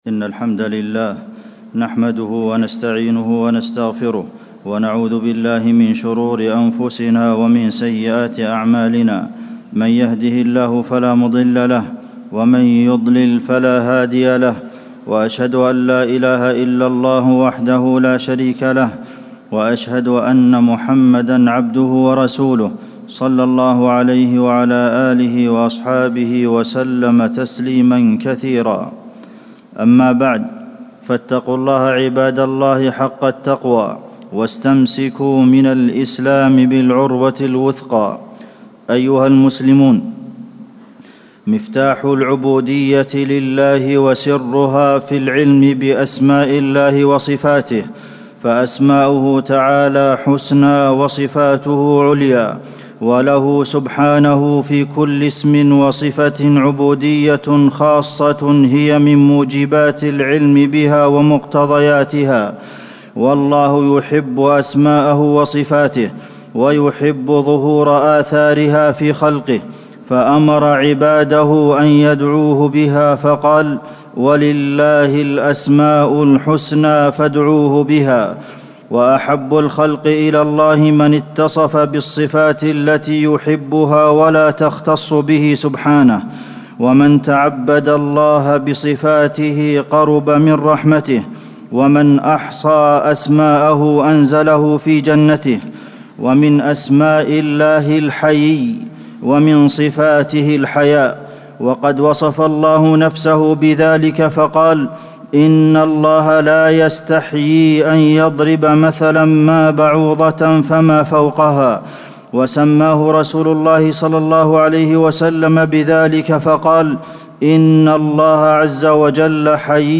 تاريخ النشر ٧ جمادى الآخرة ١٤٣٩ هـ المكان: المسجد النبوي الشيخ: فضيلة الشيخ د. عبدالمحسن بن محمد القاسم فضيلة الشيخ د. عبدالمحسن بن محمد القاسم فضل الحياء